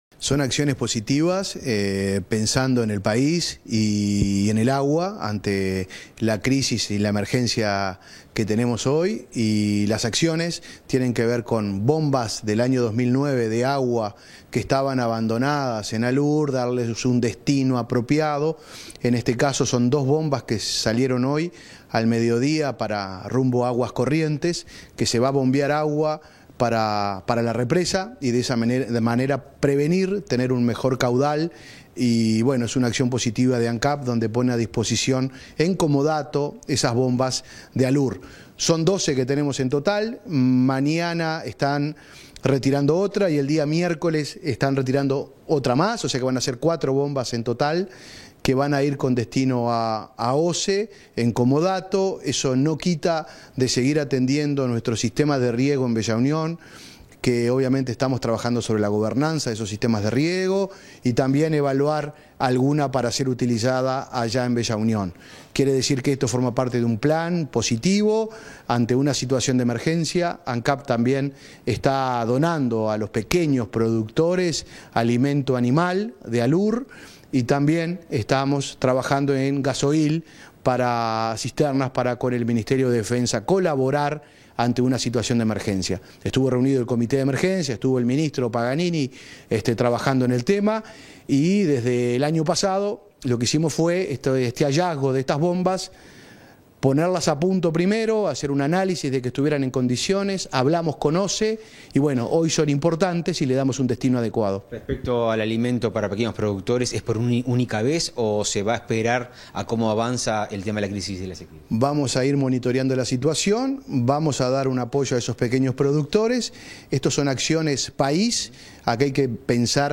Entrevista al vicepresidente de Ancap, Diego Durand